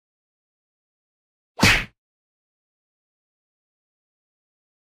Hiệu ứng âm thanh cú Đá